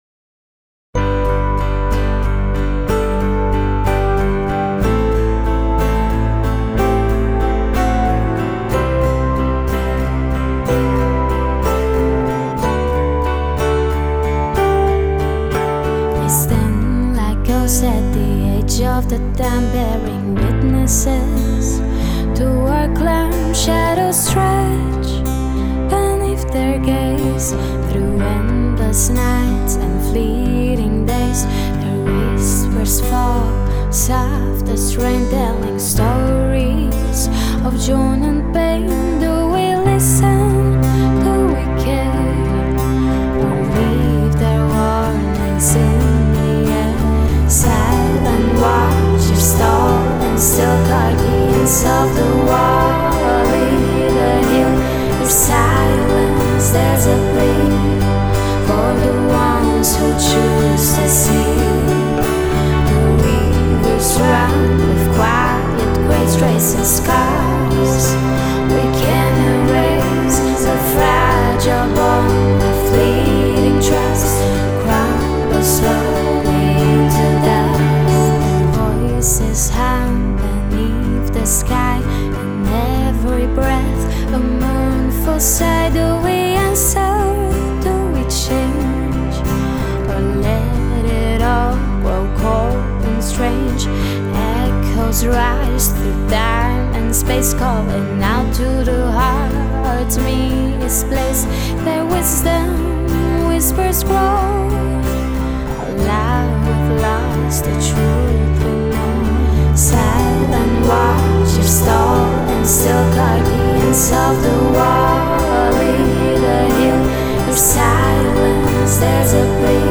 Vocal
Guitar
Piano
Bassguitar